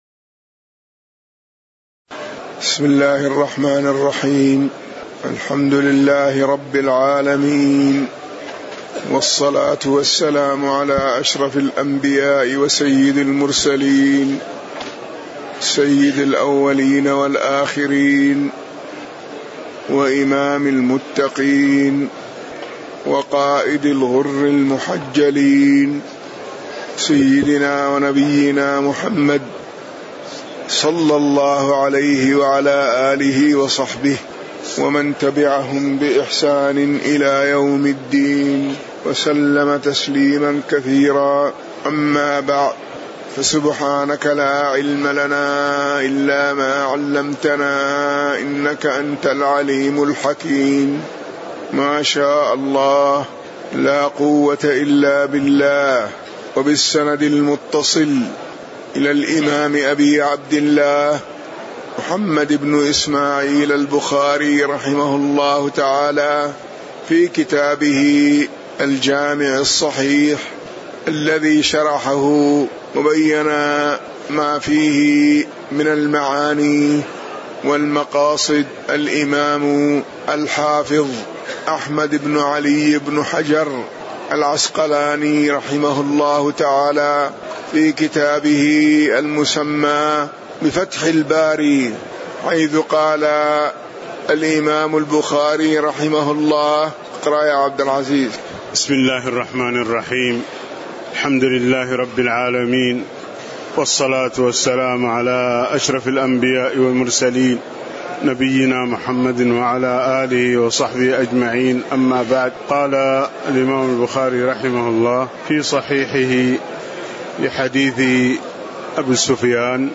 تاريخ النشر ٥ محرم ١٤٣٩ هـ المكان: المسجد النبوي الشيخ